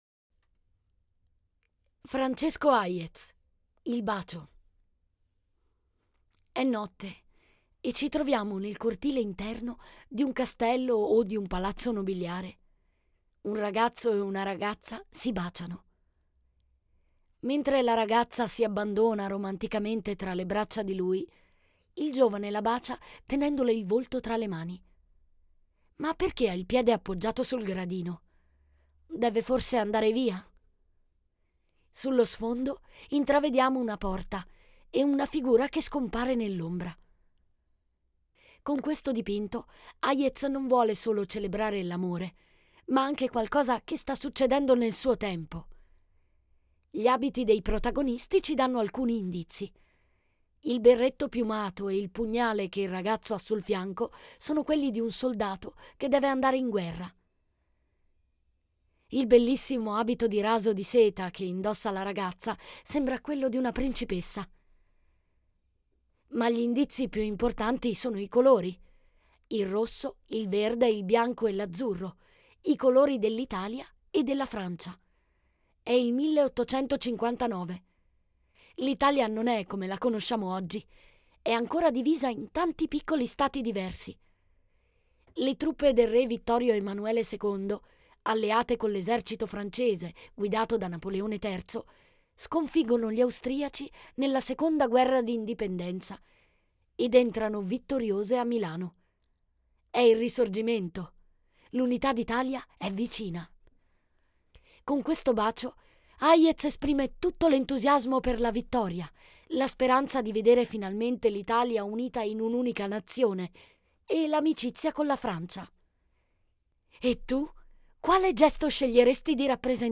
Human Audio